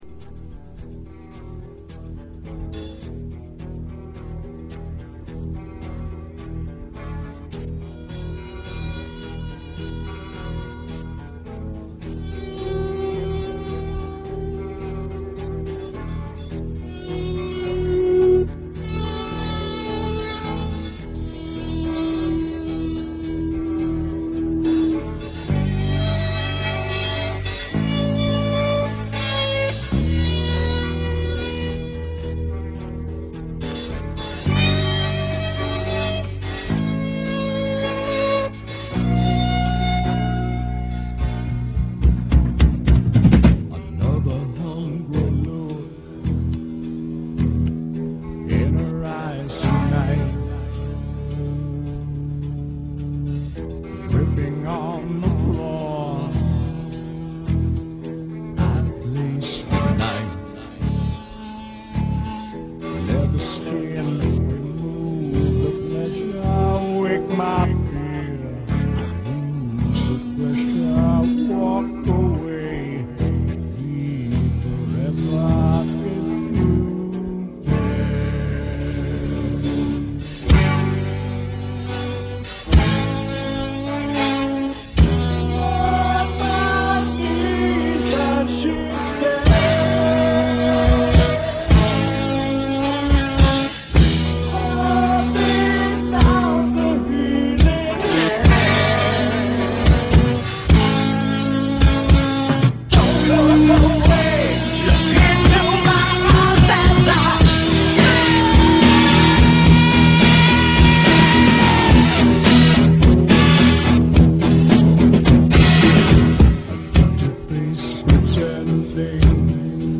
Demo Version des Songs mit Saxaphon-Einsätzen.